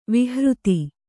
♪ vihřti